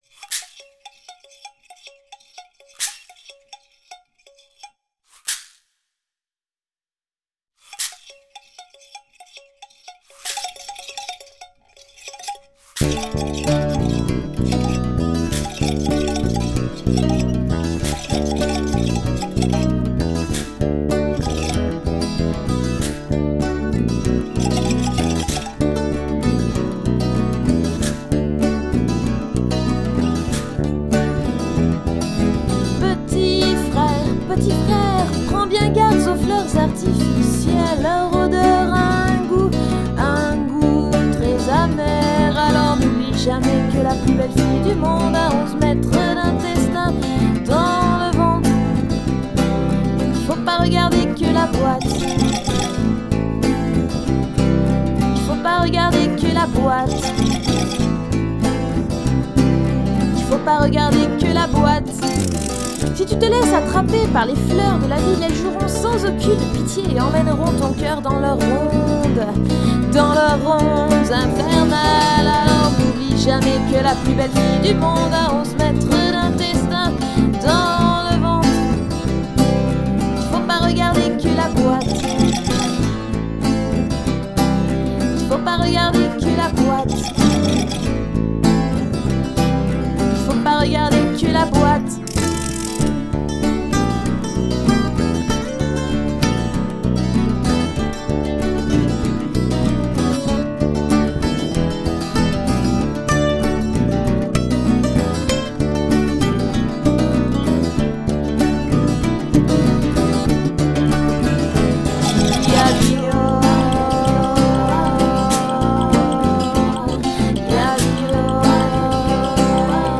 chant, guitare, bruitages
guitares, laud
guitare basse, percussions